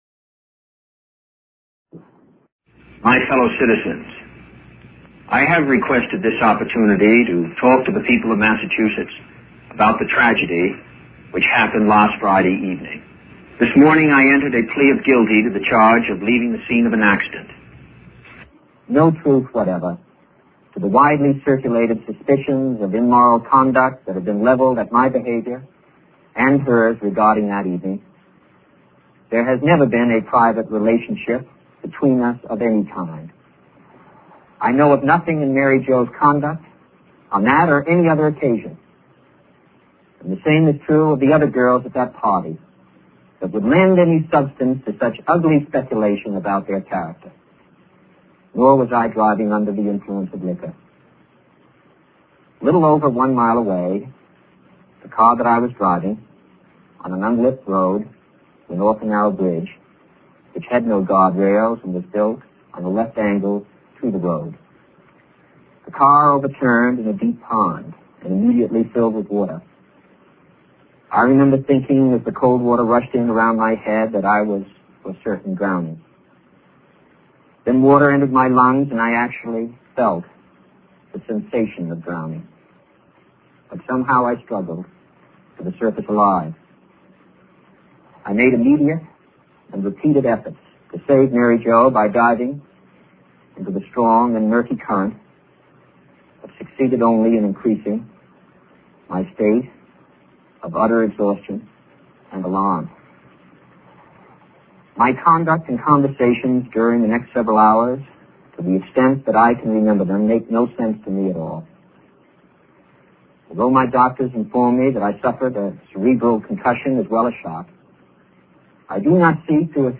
broadcast nationally from Joseph P. Kennedy's home on 25 July 1969